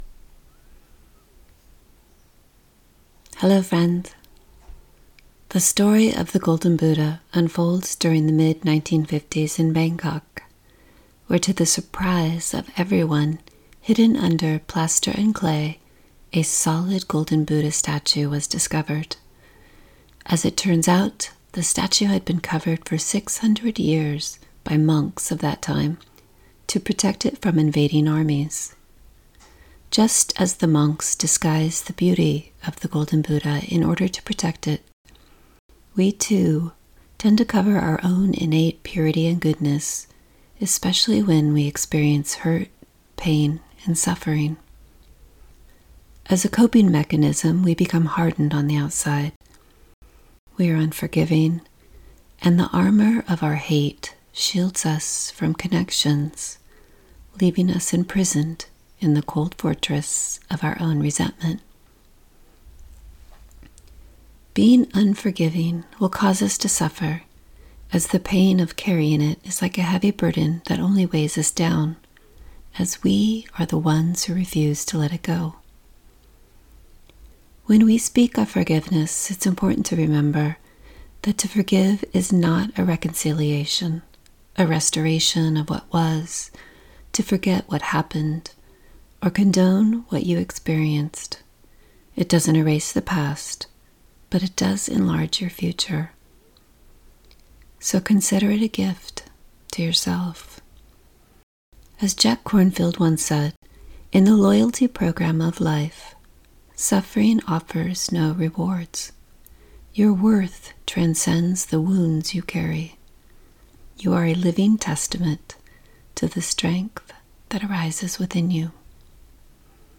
Universal-Buddhist-Prayer-of-Forgiveness.mp3